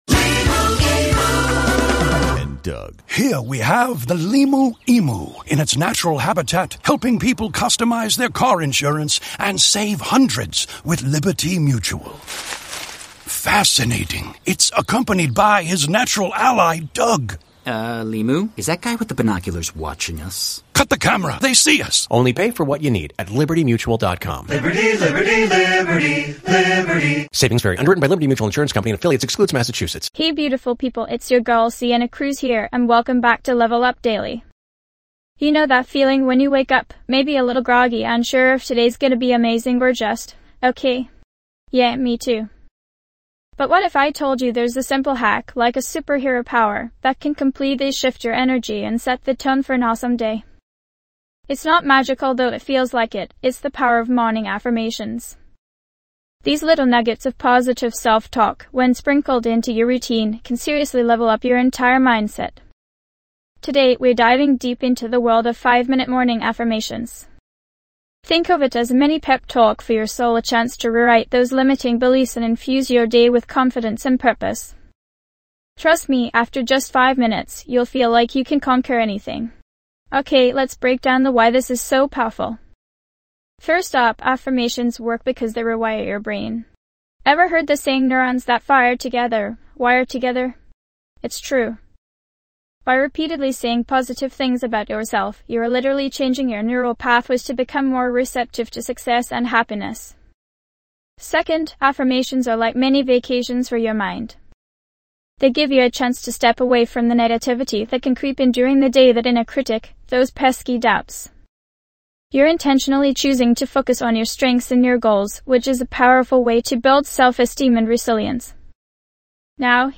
Podcast Category:. Self-improvement, Personal Development, Motivational Talks
This podcast is created with the help of advanced AI to deliver thoughtful affirmations and positive messages just for you.